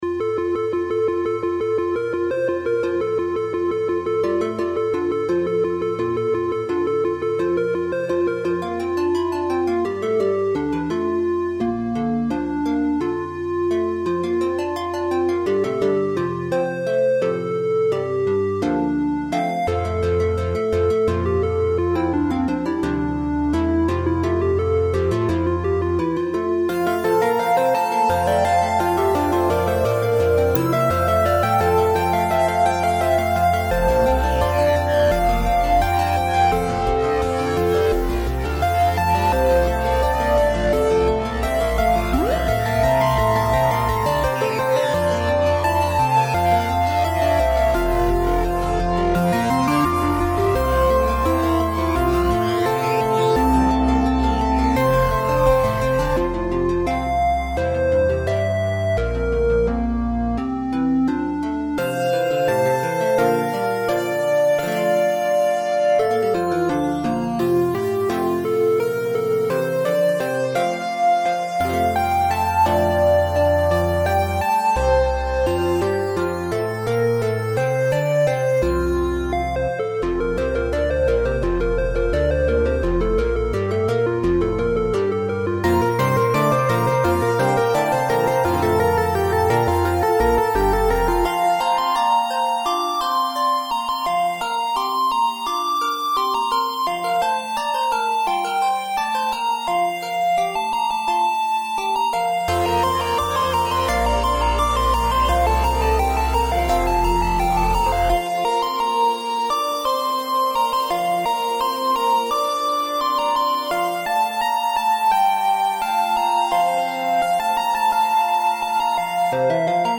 Blissful Morning (Synthesizer Only version) - Electronic
Blissful Morning (Opus 15, synthesizer version) written entirely for synthesizers : lead, chord, pulse, EDM basses and a little dose of percussion pad. The piece is written entirely in F major, and was based on the classical version of the composition written for piano, flute, and violoncello.